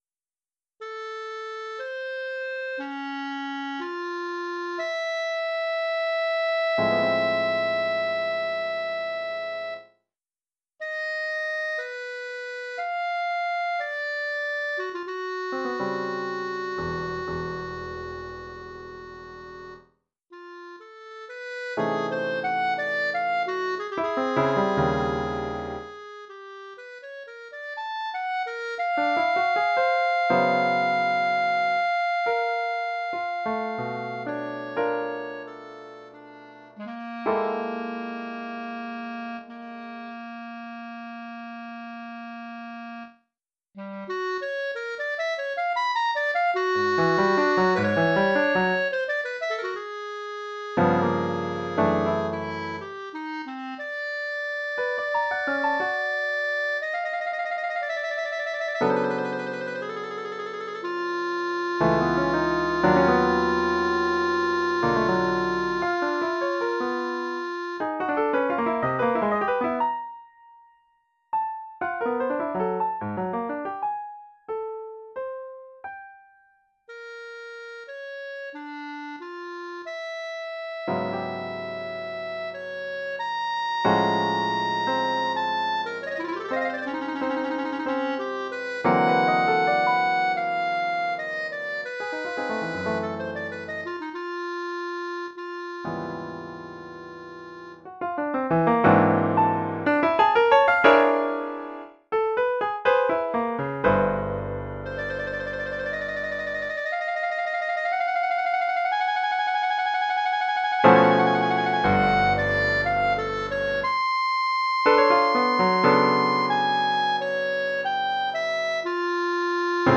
Formule instrumentale : Clarinette et piano
Oeuvre pour clarinette et piano.